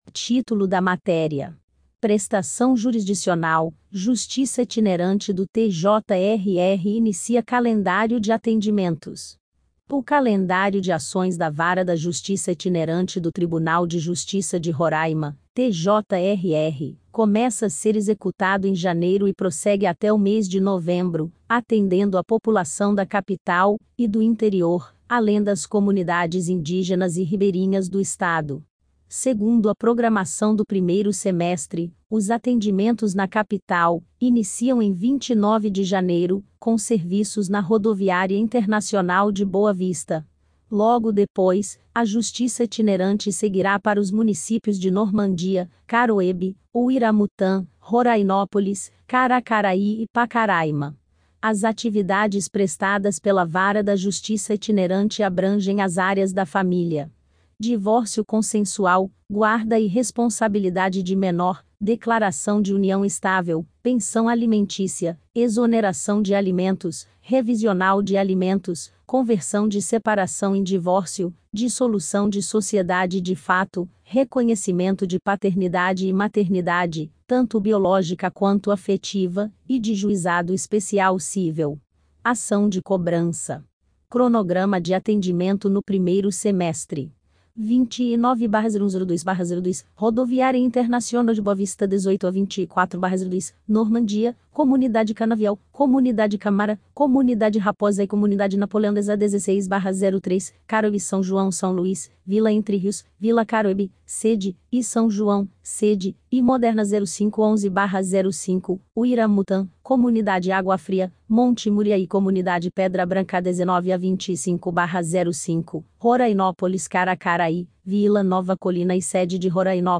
PARTICIPAO_SOCIAL_IA.mp3